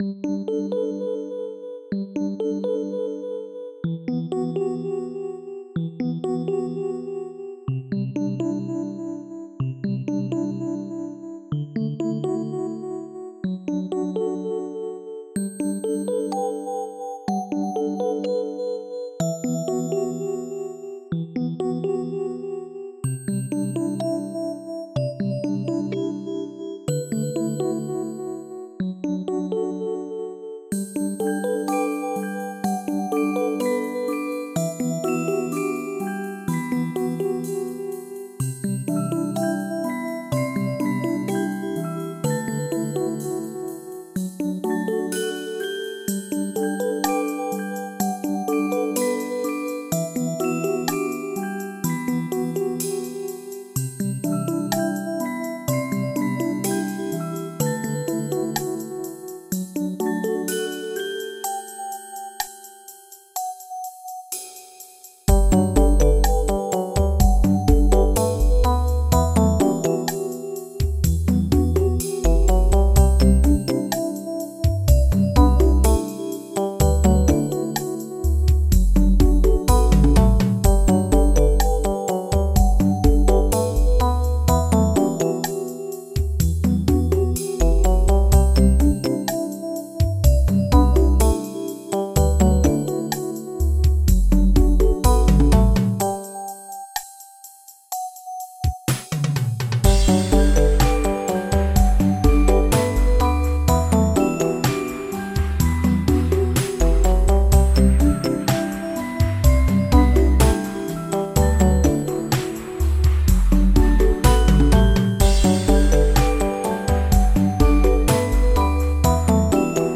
• Tracked music in any styles.